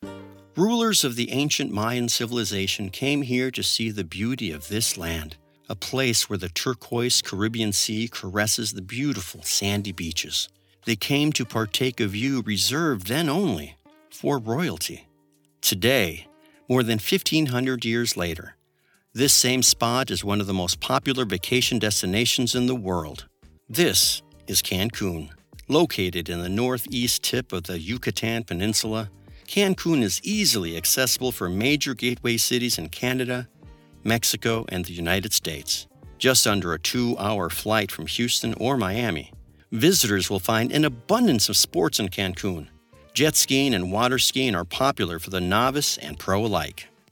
documentary voice over, documentary narrator, cinematic narration, deep authoritative voice, storytelling voice, natural narration
Documentary Narration
Grounded. Trustworthy. Built to Tell Stories That Matter.
Documentary Demos
Cancun-authoritative narration, cinematic storytelling voice, natural documentary voice, history documentary narration, educational narration voice
Documentary--Cancun-authentic-warm-grounded-authoritative.mp3